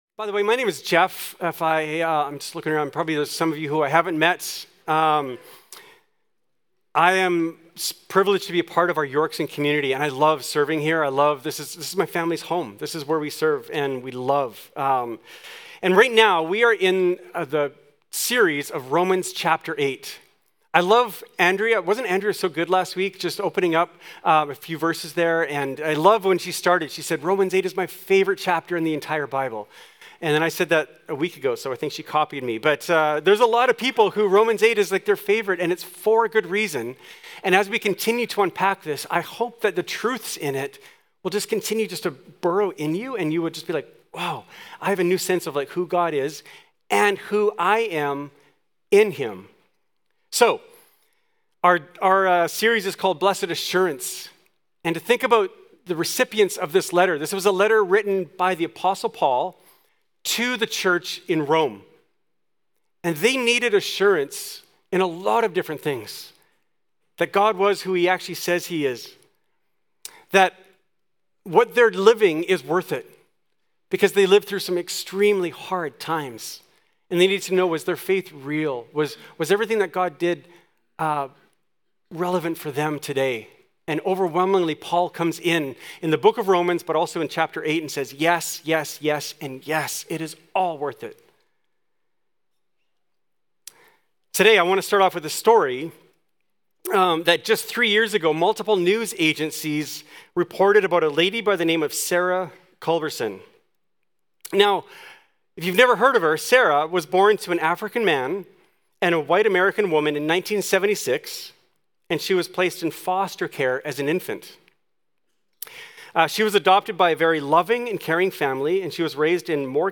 Yorkson Sermons | North Langley Community Church